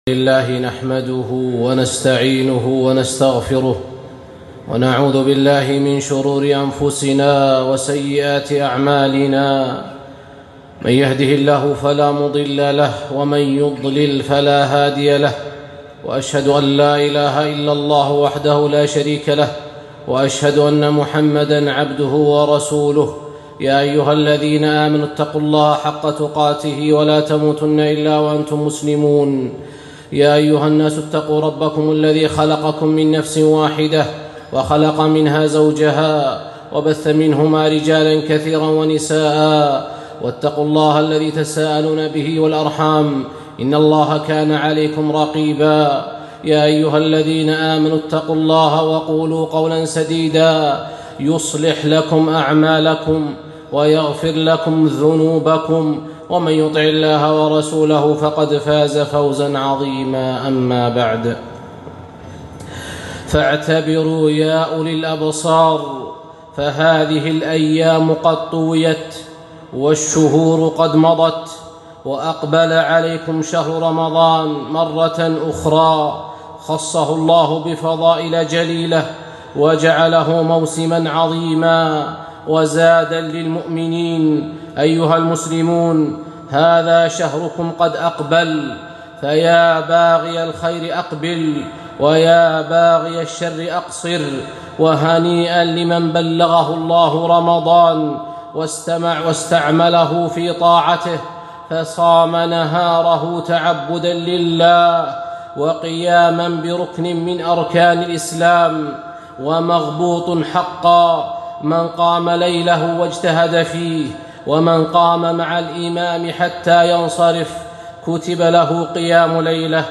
خطبة - موسم عظيم